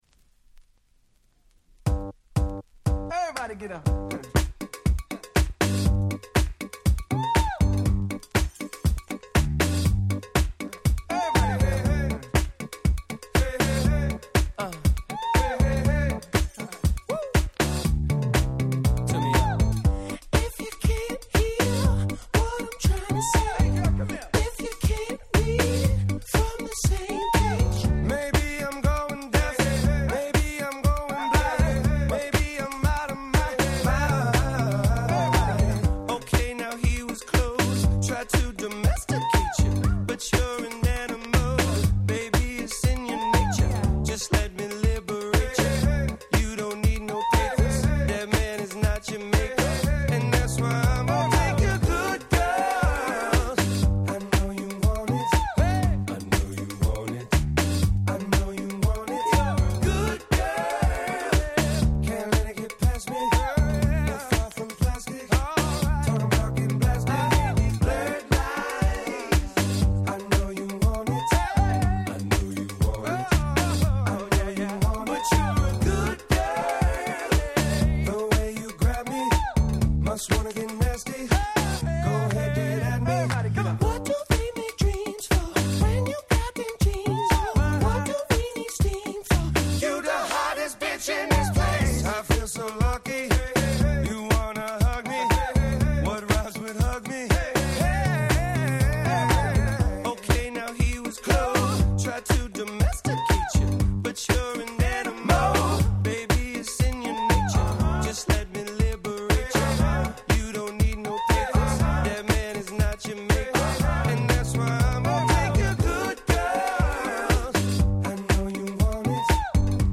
試聴ファイルは別の盤から録音してございます。
13' 大ヒットR&B♪